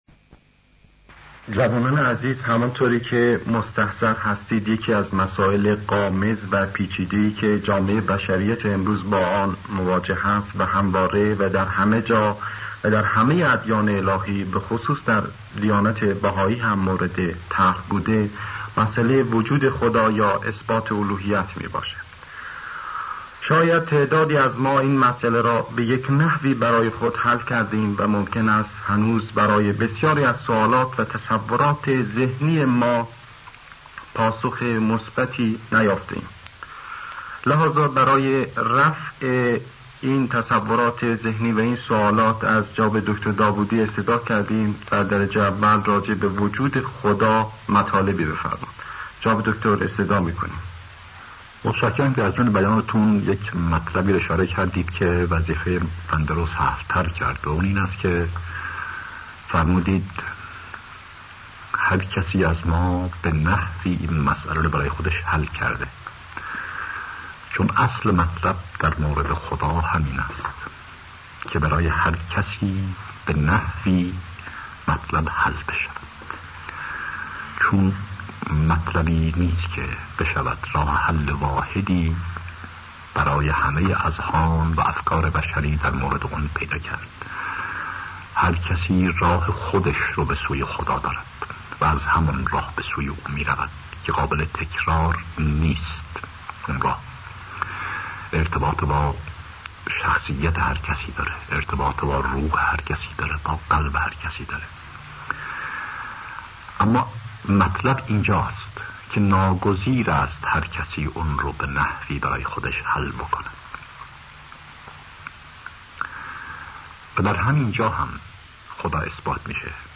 سخنرانی هایی پیرامون عقاید بهائی